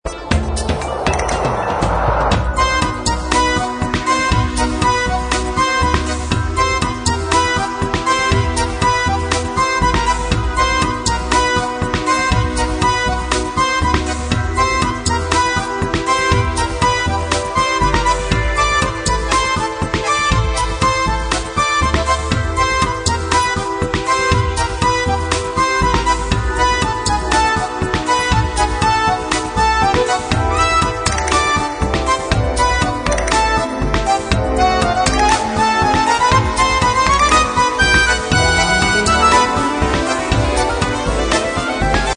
Saxophone,
Sitar,